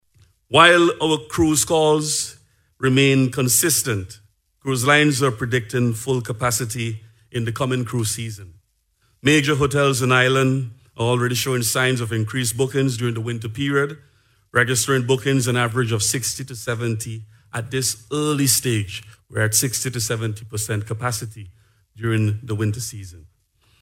Minister of Tourism, Carlos James made the disclosure while delivering the keynote address at the State of the Tourism Industry Address and Stakeholders’ Conference, last week at the Methodist Church Hall in Kingstown.